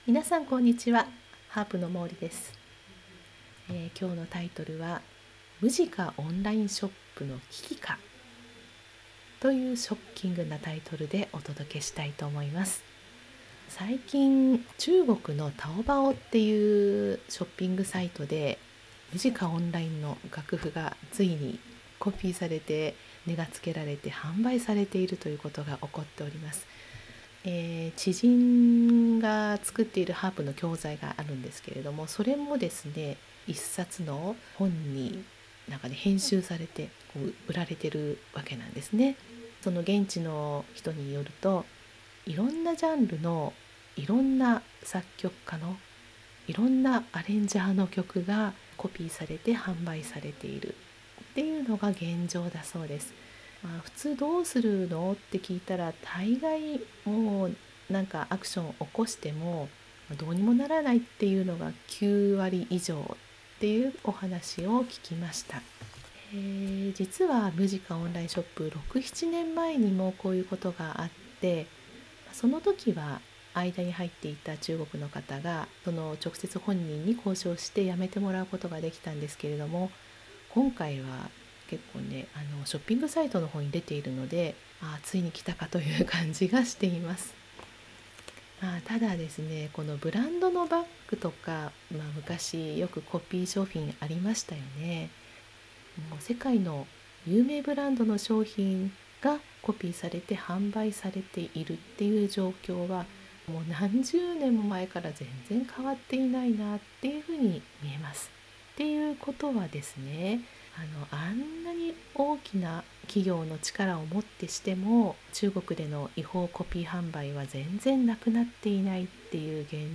（音声ブログ）ムジカオンラインショップの危機か！？
今日の音声ブログは、最近ムジカオンラインショップに起こっている事をお話してみました。